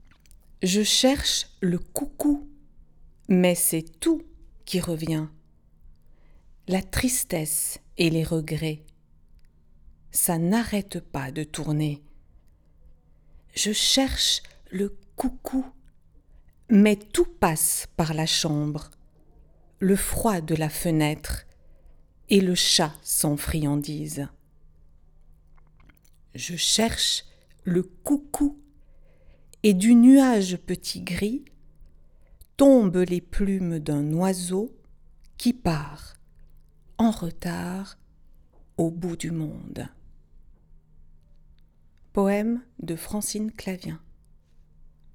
Fichier audio du poème utilisé dans le parcours Le poème mis en voix FRA 3-4